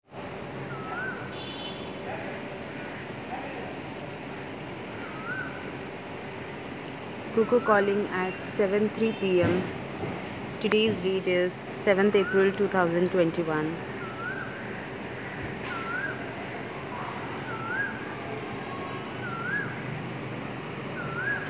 The Cuckoo's calling + other birds call